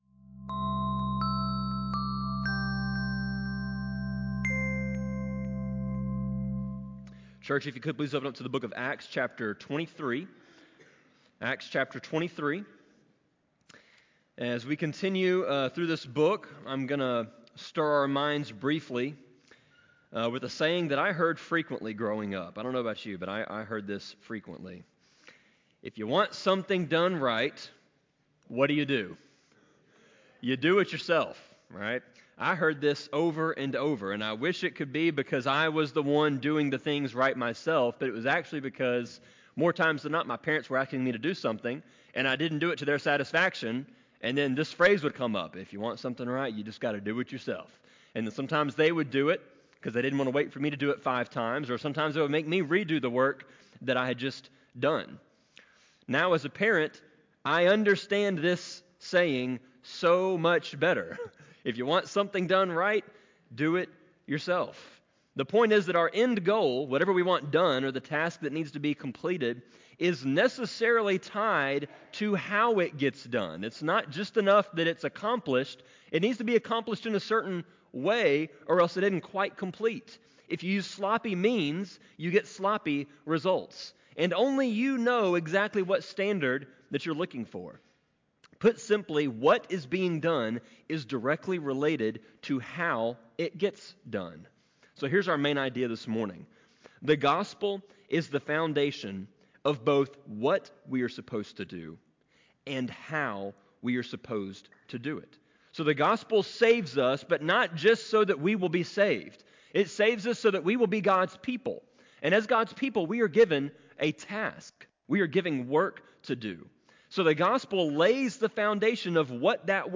Sermon-24.9.29-CD.mp3